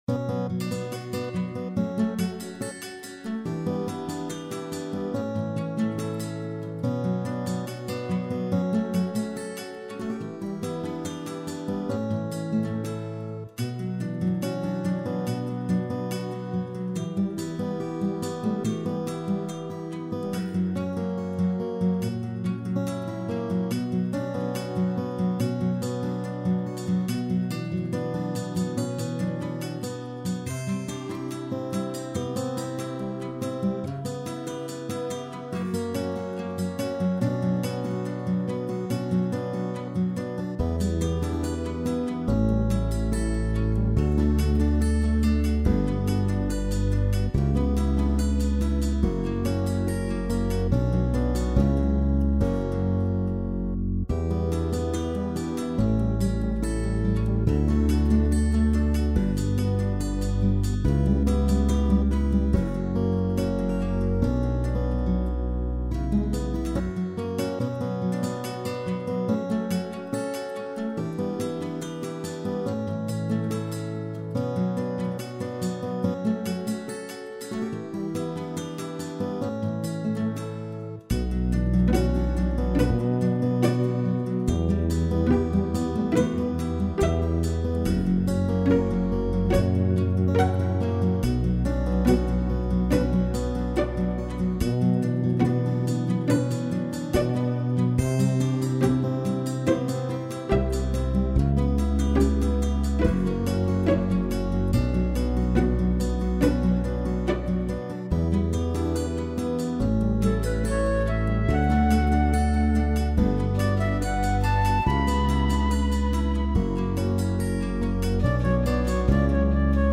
Пойте караоке
минусовка версия 34005